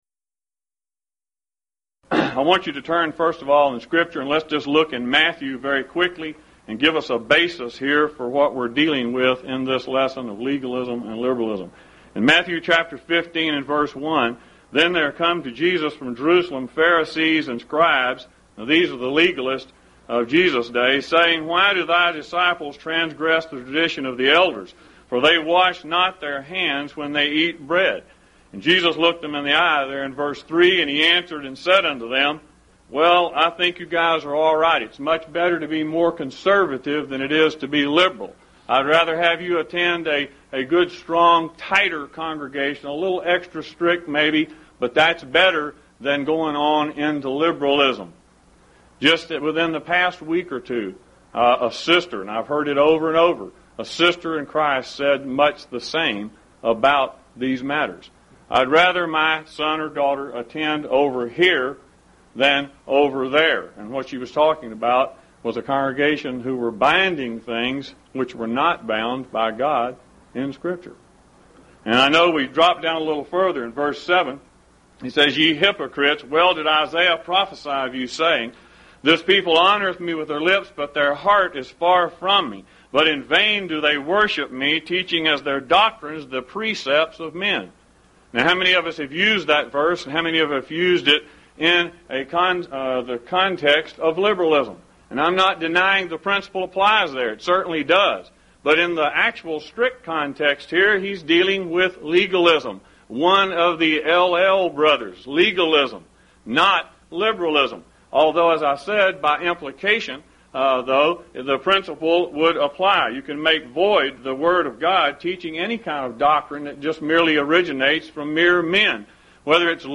Event: 1st Annual Lubbock Lectures
lecture